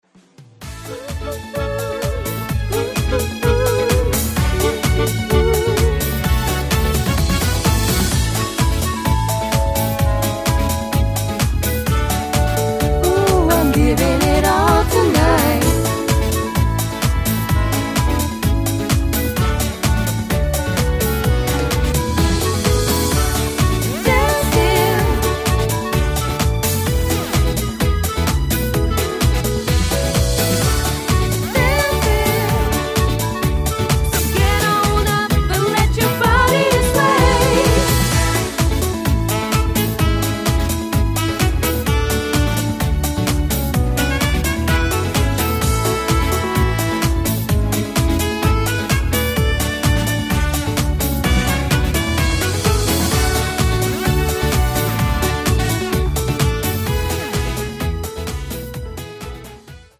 (High Key)
Singing Calls